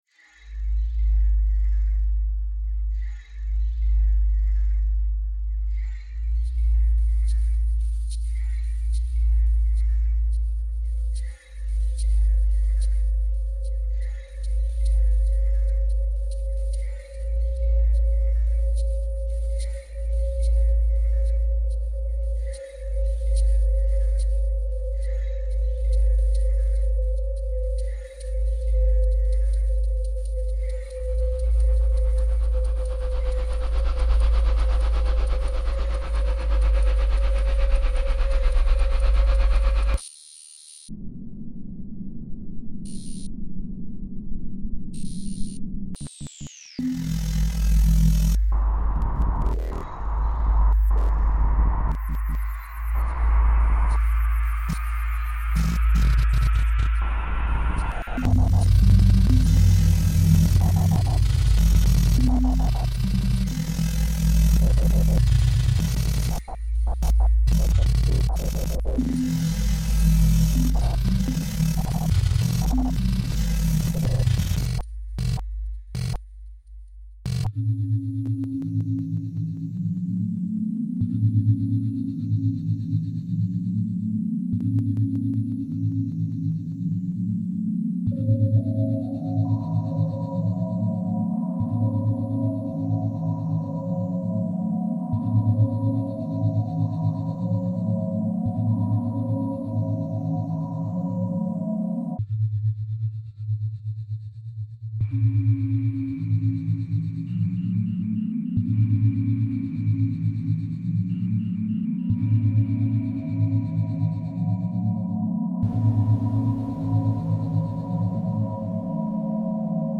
Palermo airport reimagined